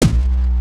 Boogz Kick.wav